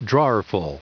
Prononciation du mot drawerful en anglais (fichier audio)
Prononciation du mot : drawerful